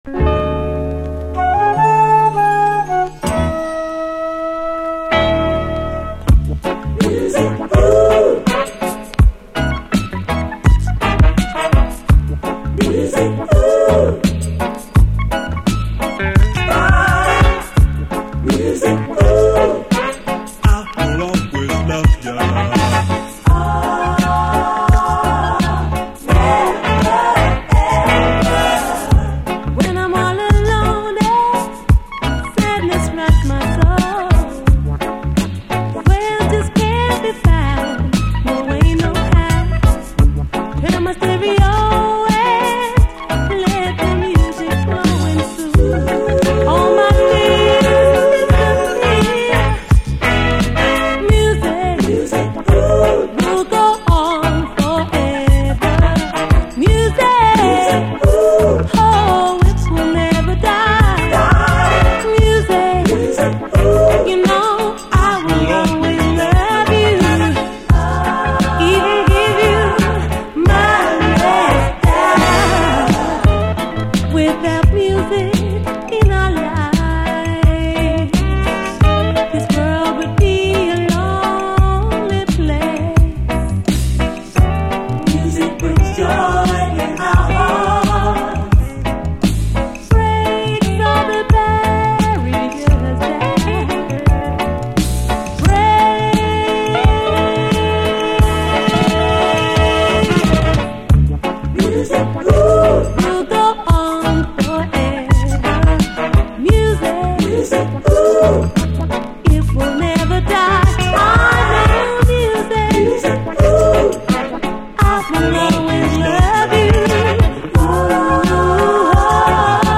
REGGAE
全編極上のラヴァーズ名盤2ND！